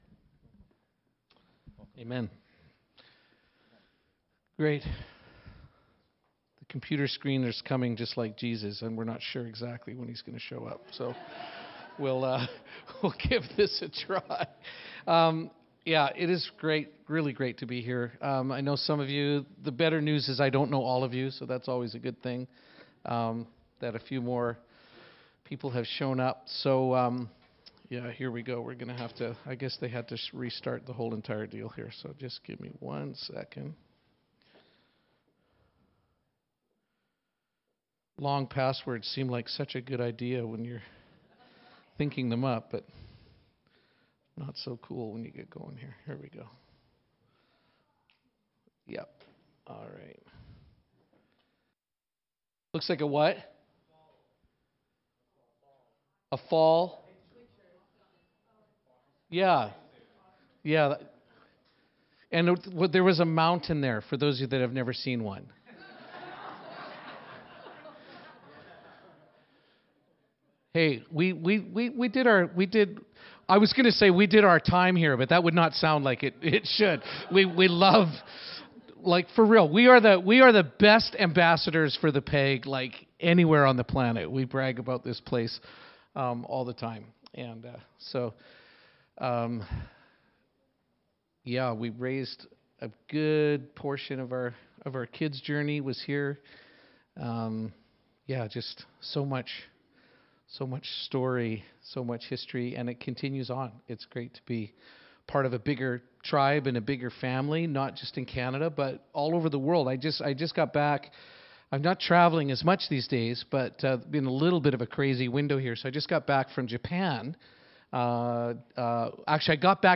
Service Type: Downstairs Gathering